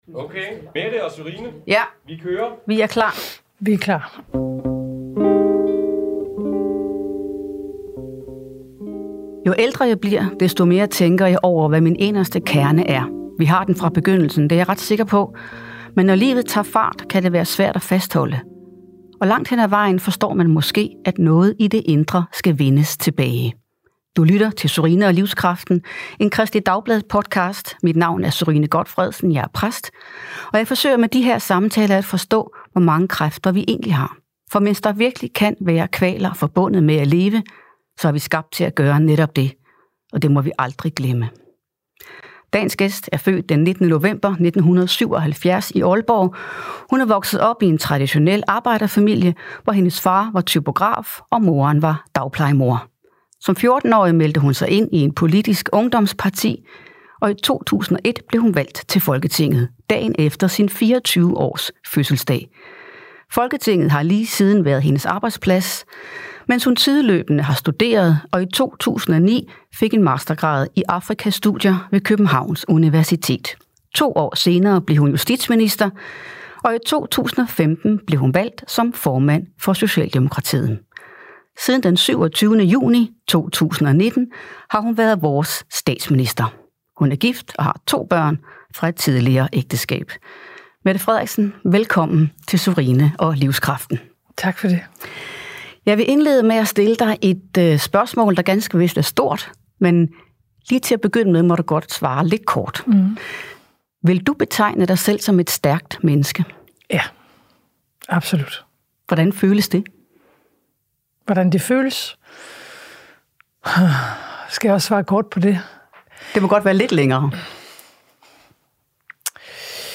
Statsminister Mette Frederiksen er gæst